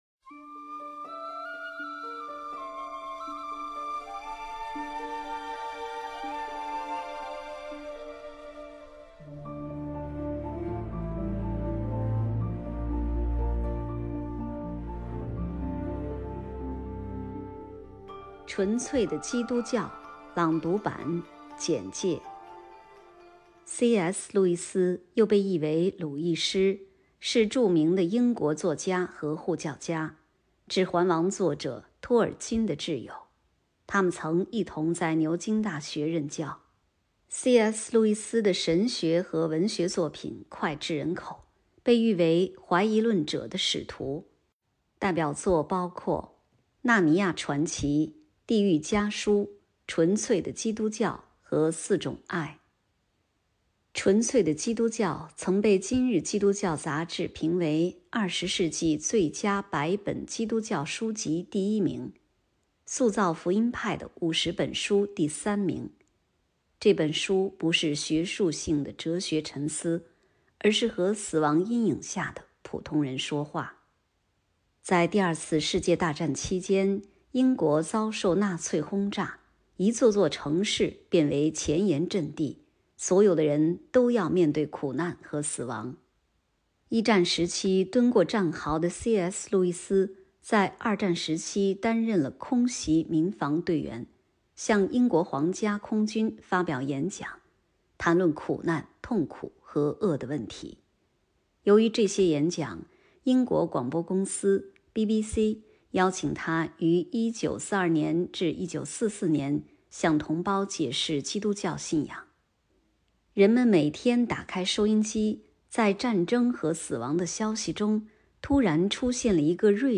《返璞归真：纯粹的基督教》朗读版 – 基督、使命与教会